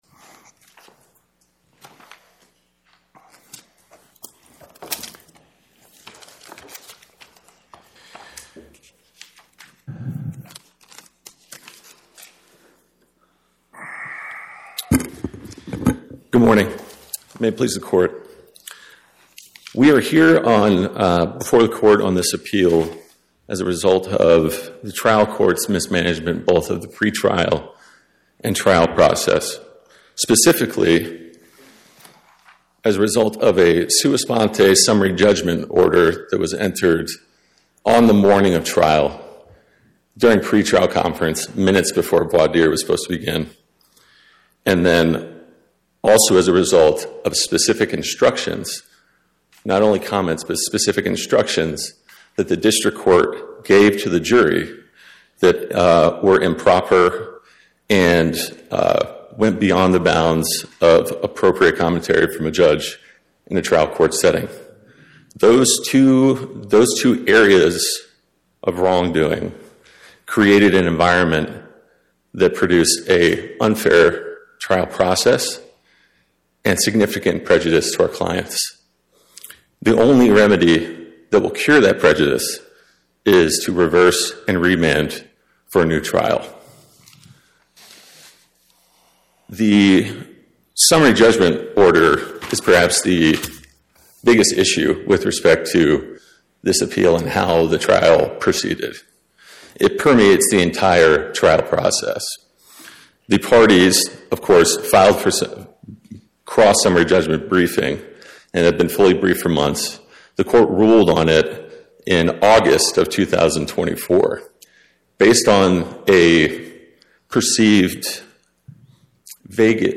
My Sentiment & Notes 25-1741: FA ND Chev, LLC vs BAPTKO, Inc. Podcast: Oral Arguments from the Eighth Circuit U.S. Court of Appeals Published On: Thu Mar 19 2026 Description: Oral argument argued before the Eighth Circuit U.S. Court of Appeals on or about 03/19/2026